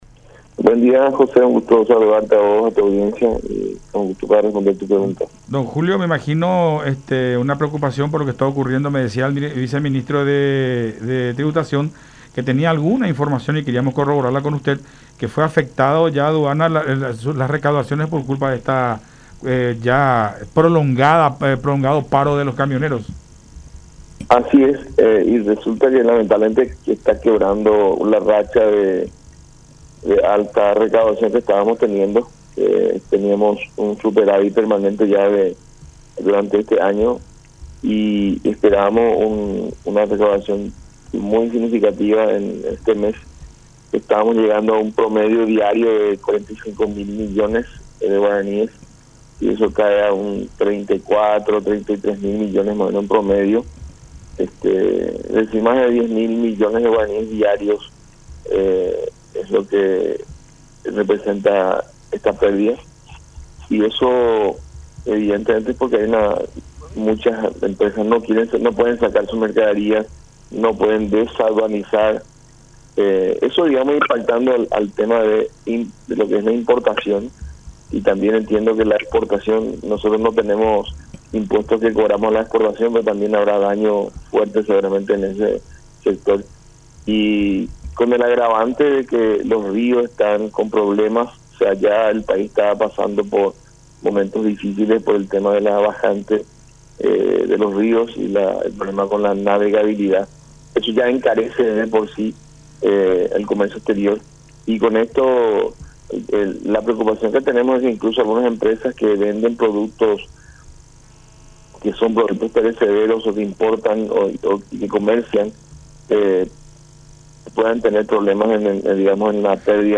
“Con el tema del paro de camioneros se está quebrando esa racha de buenas recaudaciones que teníamos de superávit permanente. Encima, tenemos el agravante de la bajante de los ríos, el problema de la navegabilidad. Empresas que se estaban levantando posterior a la pandemia y lo que había sido la cuarentena, vuelven a tener ahora un golpe significativo y corren el riesgo de quiebra”, expuso Fernández en conversación con Enfoque 800 a través de La Unión.